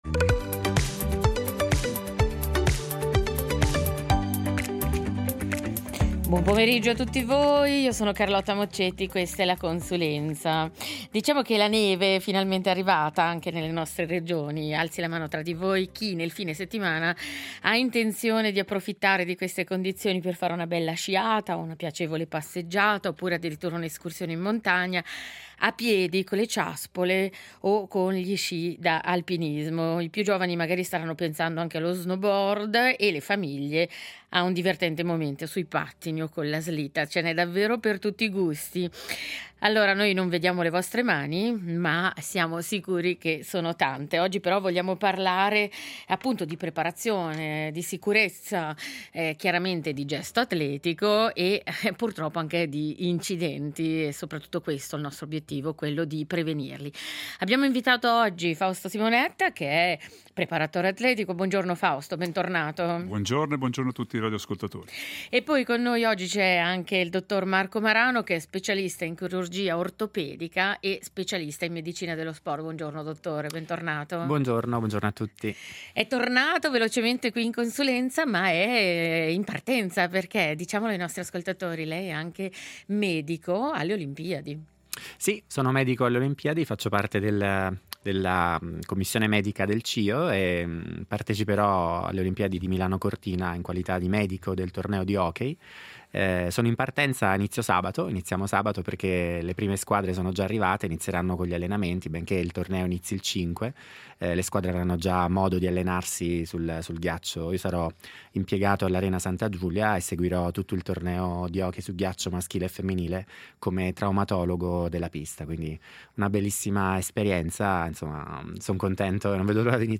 Sci, snowboard, sci alpinismo, ciaspole… Sport bellissimi, ma che ogni anno portano anche un aumento significativo degli incidenti sulle nevi. Quindi parlarliamo della bellezza degli sport invernali, ma anche di sicurezza prevenzione e preparazione, con un ortopedico che in inverno vede arrivare in ambulatorio le storie meno fortunate, e un preparatore atletico che, invece, nella preparazione e prevenzione crede quasi quanto nella neve stessa.